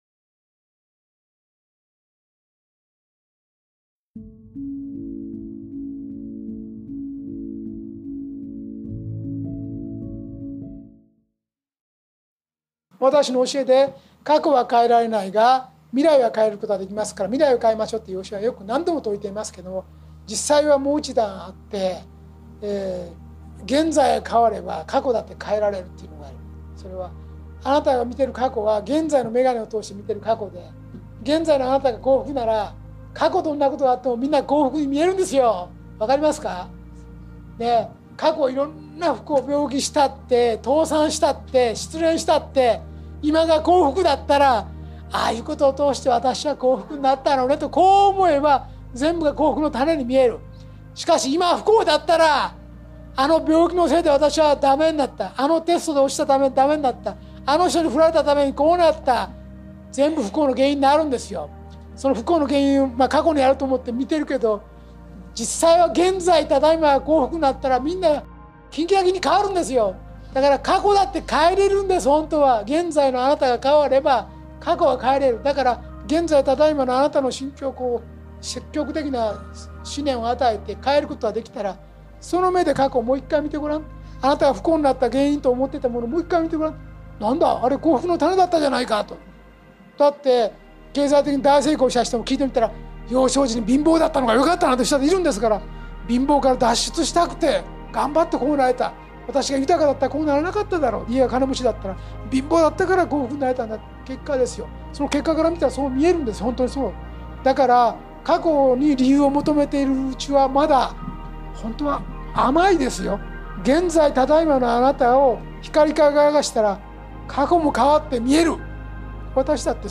ラジオ番組「天使のモーニングコール」で過去に放送された、幸福の科学 大川隆法総裁の説法集です。